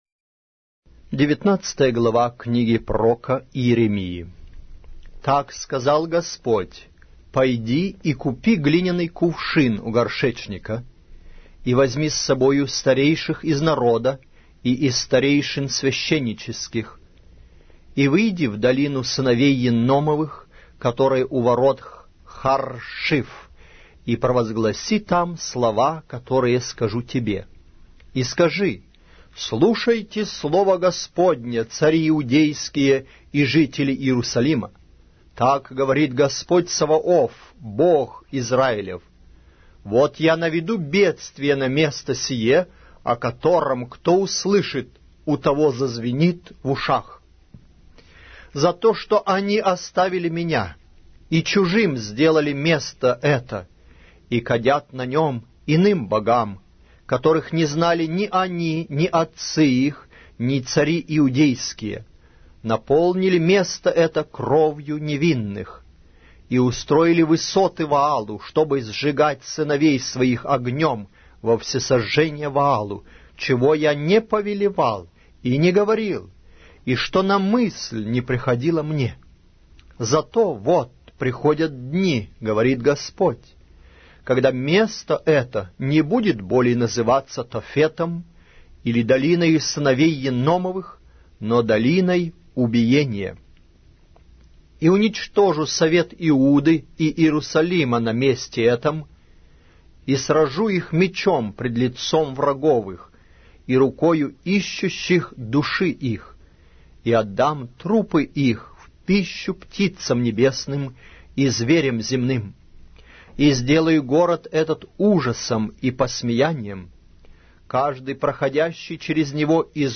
Аудиокнига: Пророк Иеремия